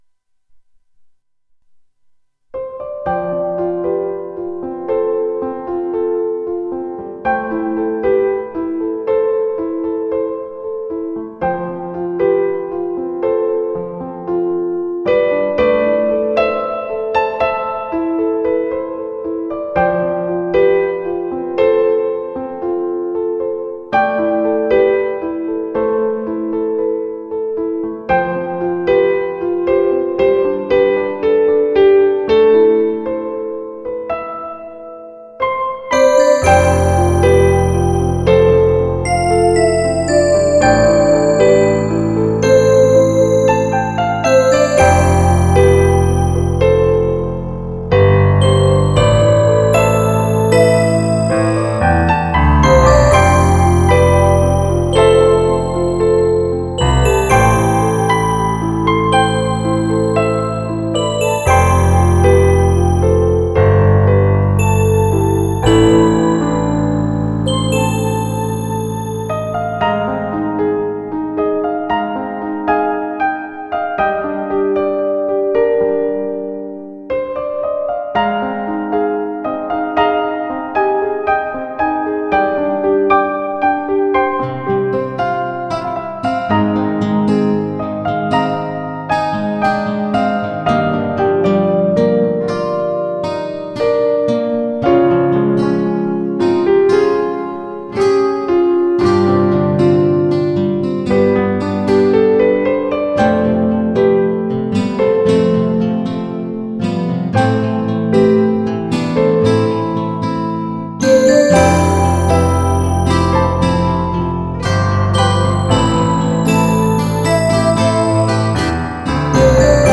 88proでもそこそこのピアノの音が出る方法を見つけたのでコピペしてやってみた。ノベルゲーのイベントとかの曲の雰囲気。